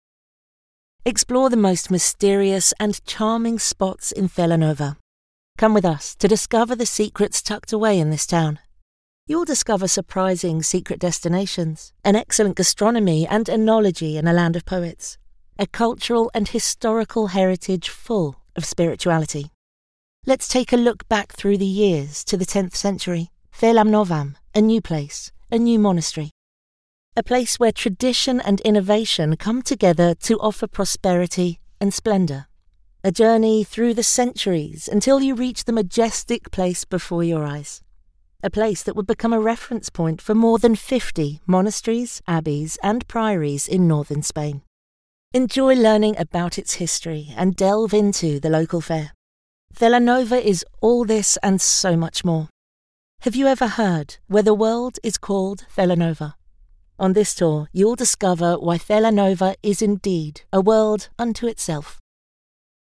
Anglais (britannique)
Audioguides